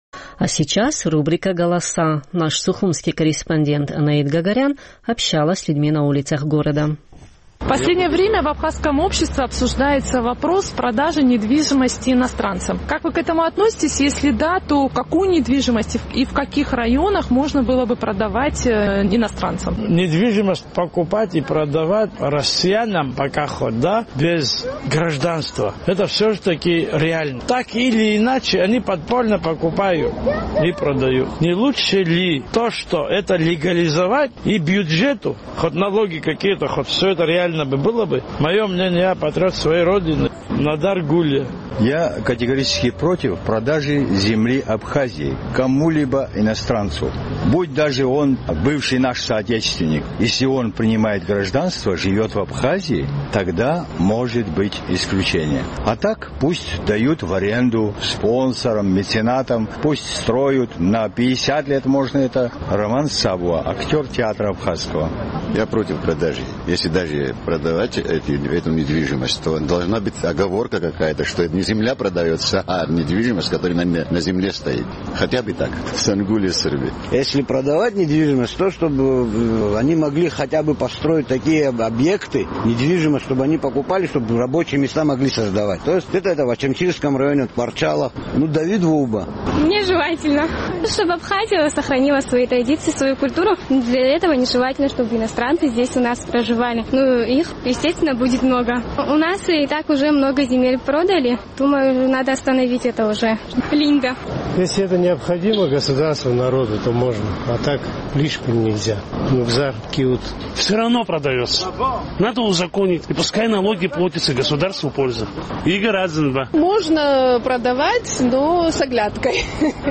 Наш сухумский корреспондент поинтересовалась мнением жителей абхазской столицы по поводу продажи недвижимости иностранцам.